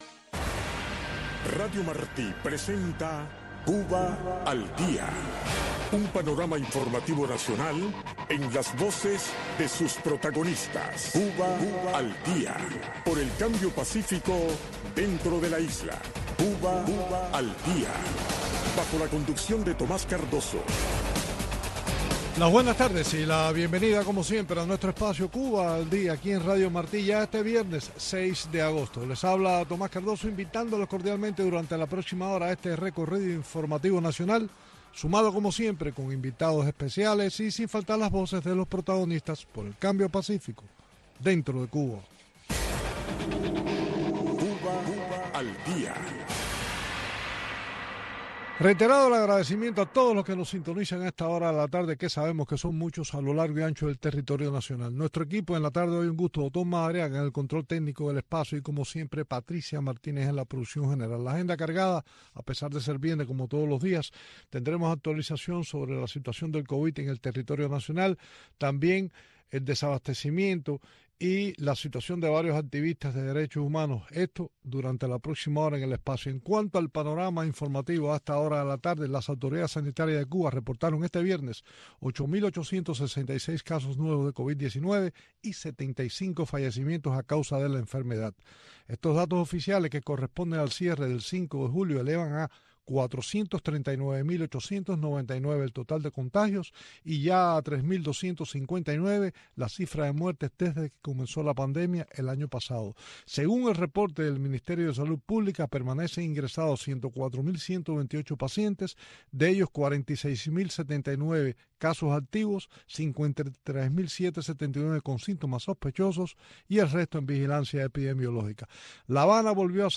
Un espacio informativo con énfasis noticioso en vivo donde se intenta ofrecer un variado flujo de información sobre Cuba, tanto desde la isla, así como desde el exterior.